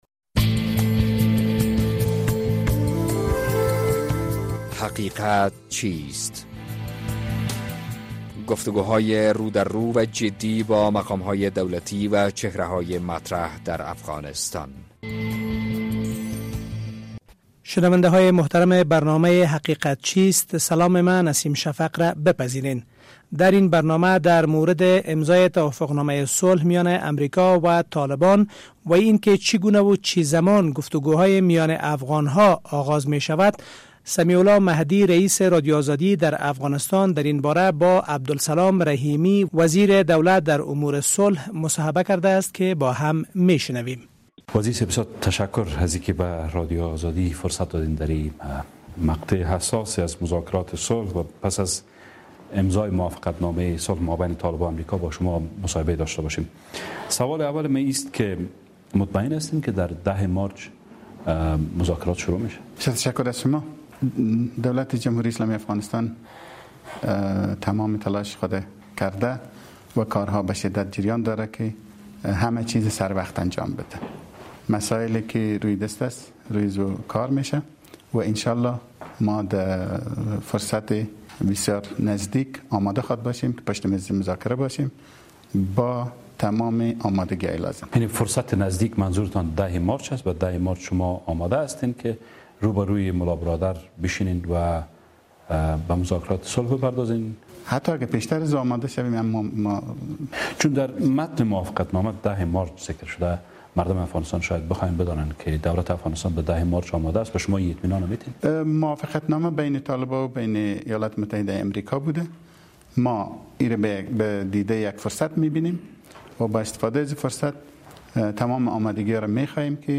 در برنامه این هفته حقیقت چیست در مورد توافق‌نامه صلح و آغاز مذاکرات میان افغان‌ها با عبدالسلام رحیمی وزیر دولت در امور صلح گفتگو کرده ایم.